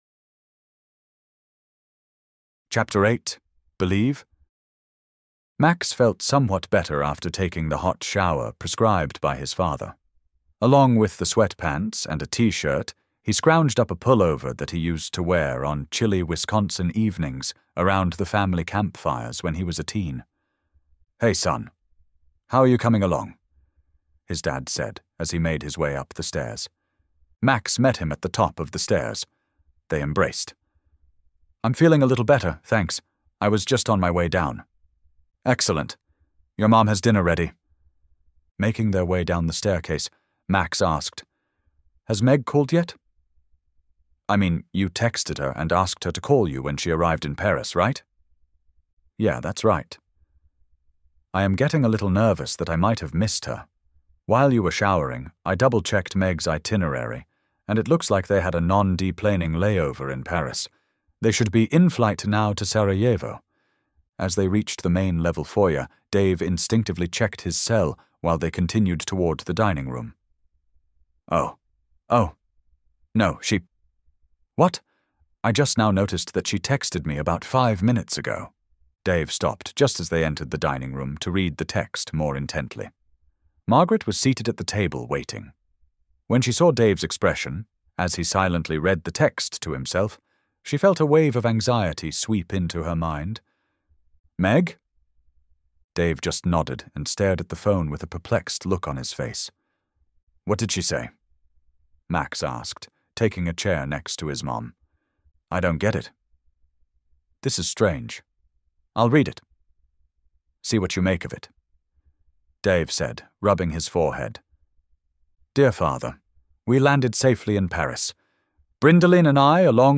As a perk to my FB friends, I want to offer you a free sample of the audiobook version this morning in which one of the story's protagonist siblings of the Manstead family, Max, is struggling to come to grips with believing in the existence of God.